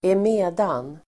Ladda ner uttalet
emedan konjunktion, since , because Uttal: [²em'e:dan] Synonymer: alldenstund, då, enär Definition: därför att because konjunktion, därför att , eftersom , emedan Idiom: because of (på grund av), just because...